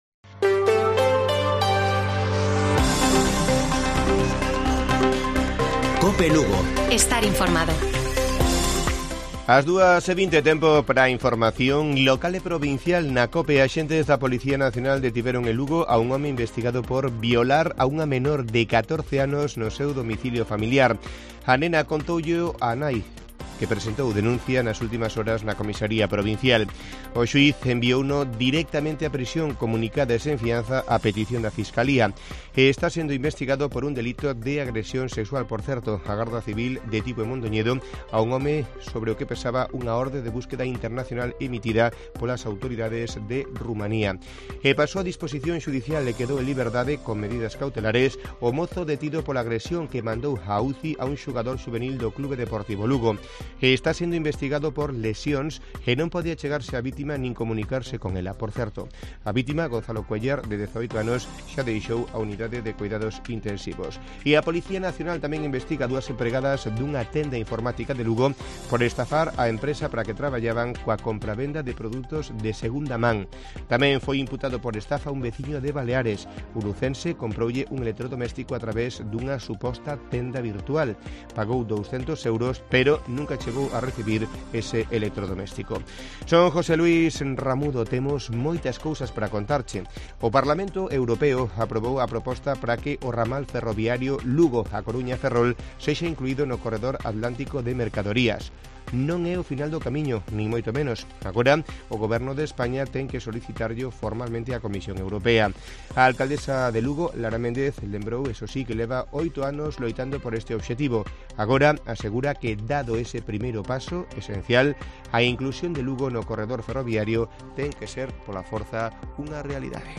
Informativo Mediodía de Cope Lugo. 19 de abril. 14:20 horas